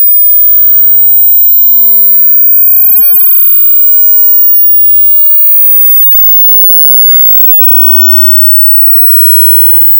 AudioCheck Tone Tests
High Frequency Tones
TEST NOTICE: Many high frequency tones are not audible to people approximately over the age of 25.